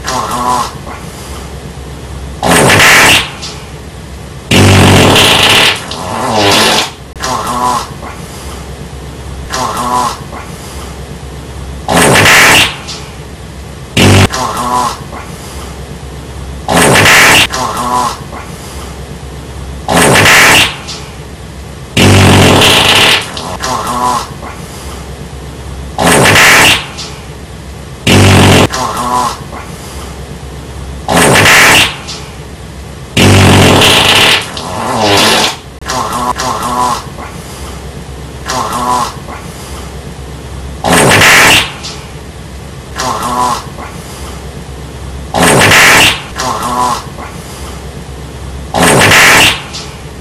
Taco Bell Aftermath - Bouton d'effet sonore